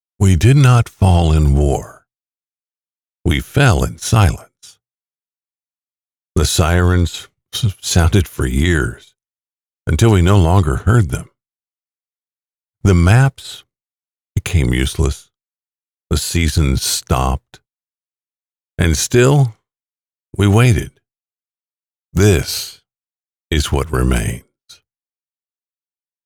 Male
Adult (30-50), Older Sound (50+)
Documentary
Dramatic Intro